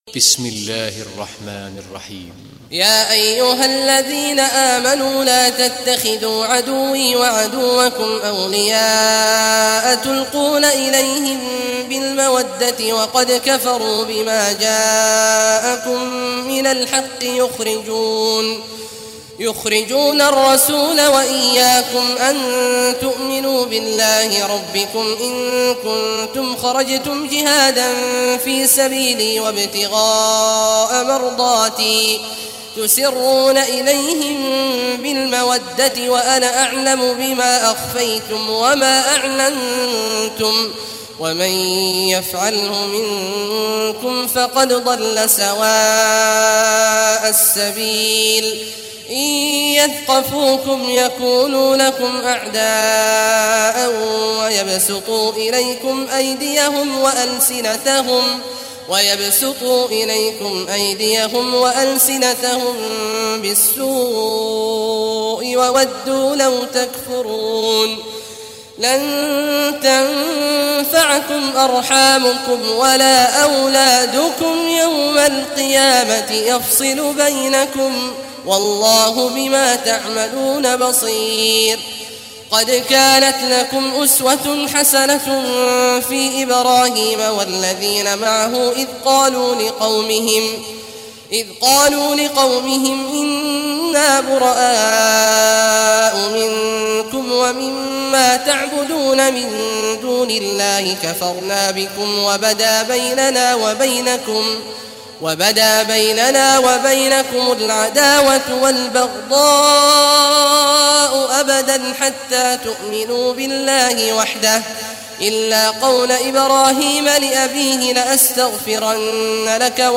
Surah Mumtahanah Recitation by Sheikh Awad Juhany
Surah Al-Mumtahanah, listen or play online mp3 tilawat / recitation in Arabic in the beautiful voice of Sheikh Abdullah Awad al Juhany.